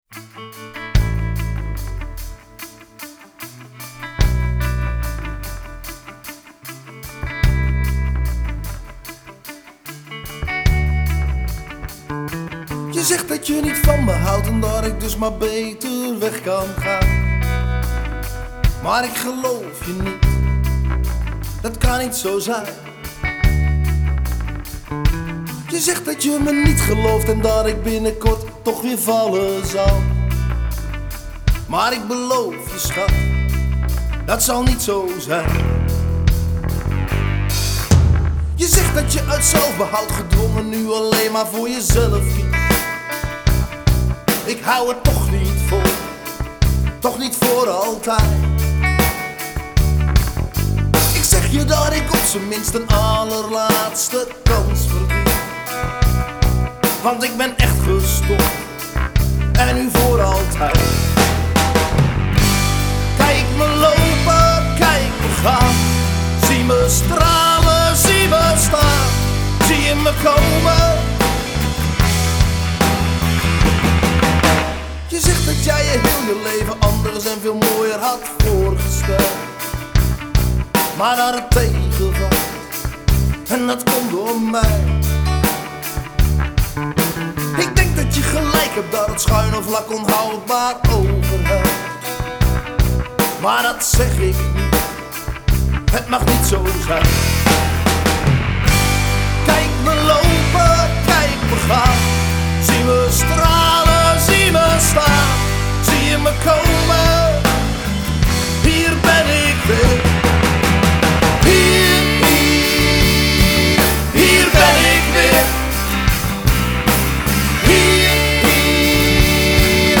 Nederlandstalige rockband